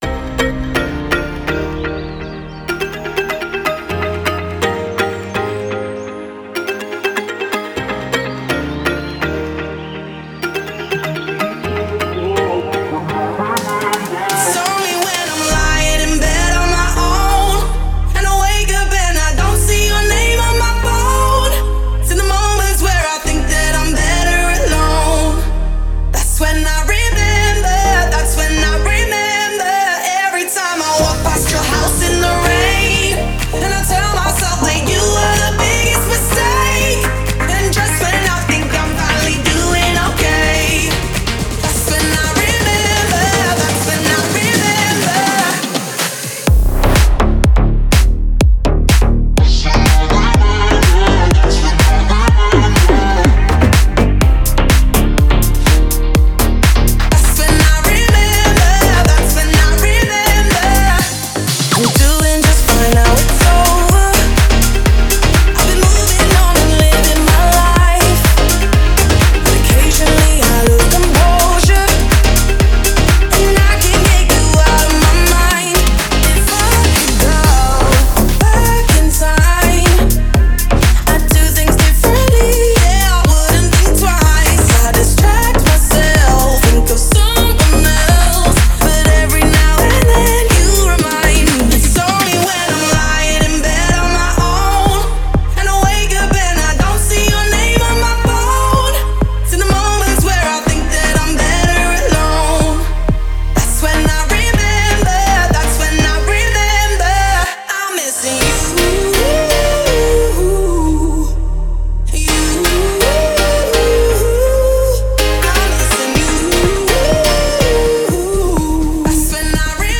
это яркий и энергичный трек в жанре EDM